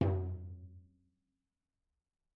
TomL_HitM_v3_rr2_Mid.mp3